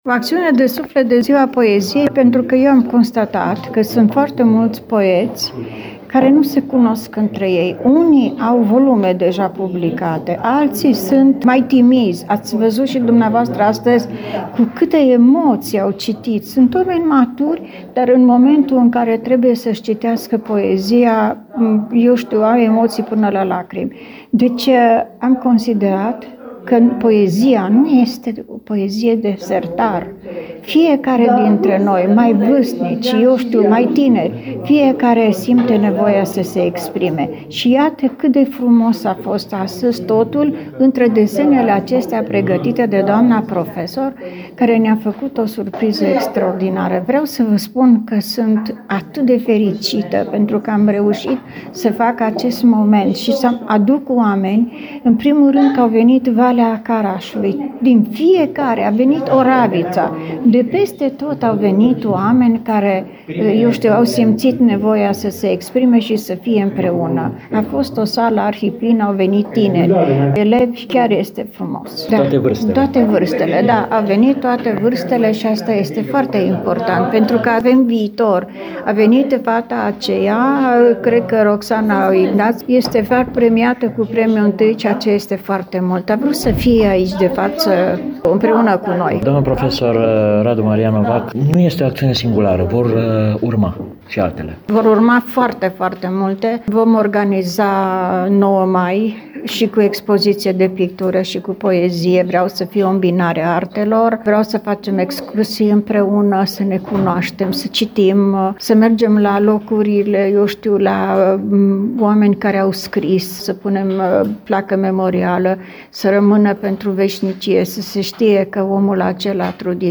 Am stat de vorbă cu unii dintre ei iar pe alții i-am ascultat recitând.